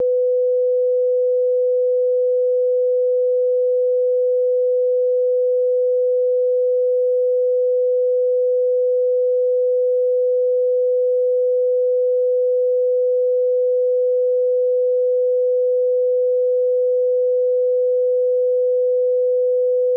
Dither Test:
A stereo channel was setup with a 500Hz sine wave at -80dBFS.
The gain of the audio samples were increased (peak -14dBFS) so that the effects shown below could be more easily heard.
1. The signal using a 24 bit depth and no dither (no distortion present).
24_bit_no_dither.wav